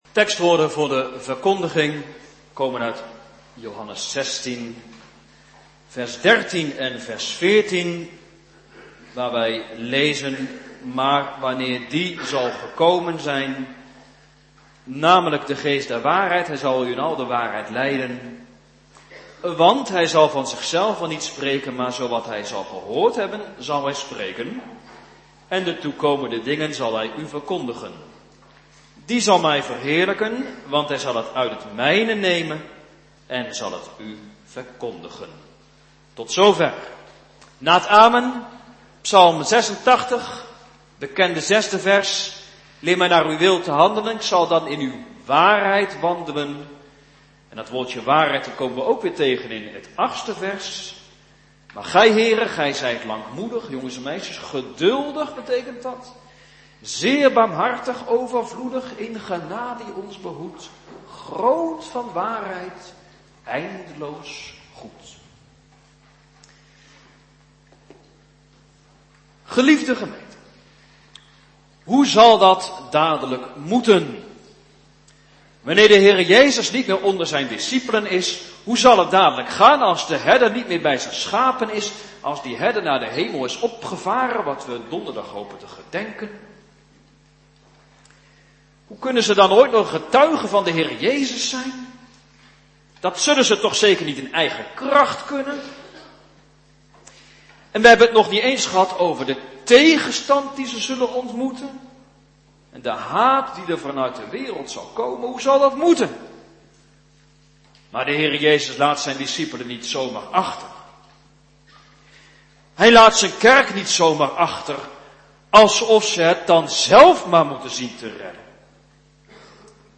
9 mei 2021 Johannes 16:13-14 Predikant